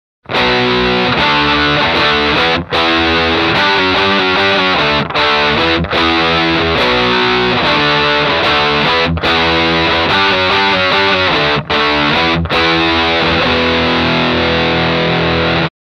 Vintage Fuzz and Distortion Blender - Bold Distortion
- Vintage Fuzz and Traditional Distortion
Demo with Humbucker Pickup 2